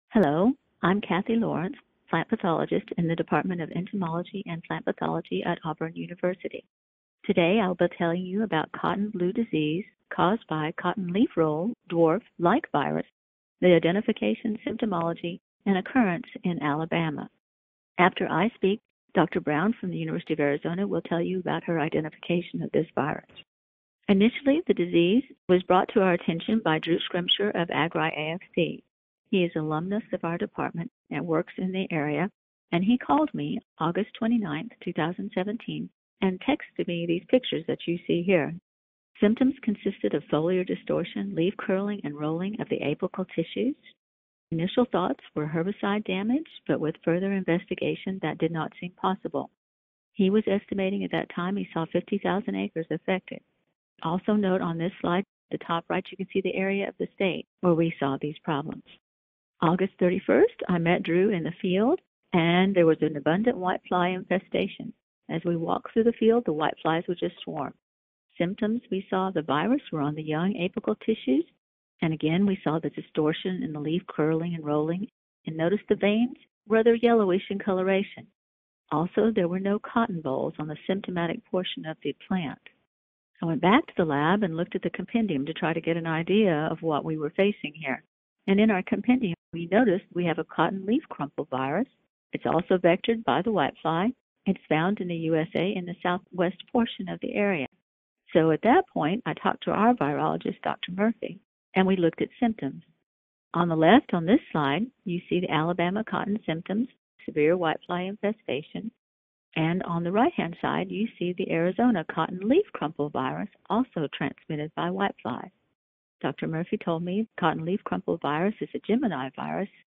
Webcast Summary Cotton blue disease, caused by Cotton leafroll dwarf like virus (CLRDV), was first detected in the United States in Alabama in 2017.